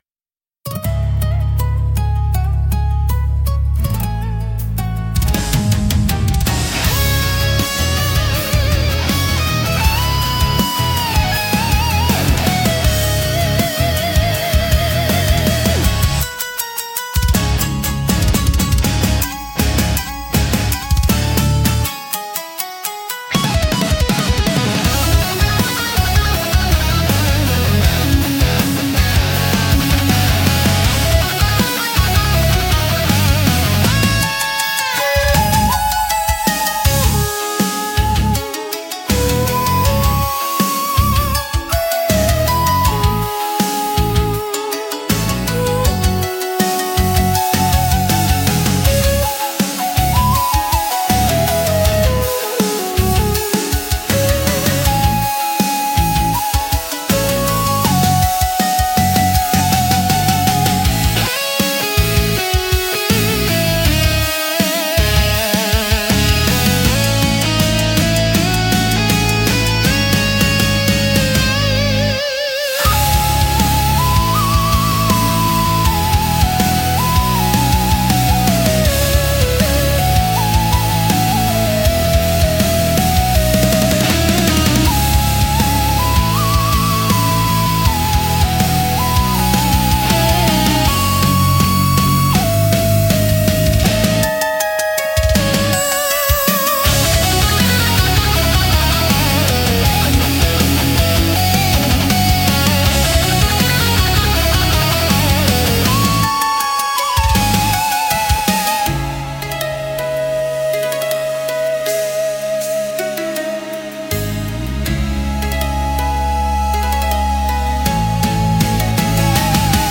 聴く人に力強さと神秘性を同時に感じさせ、日本古来の精神と現代のエネルギーを融合したインパクトを与えます。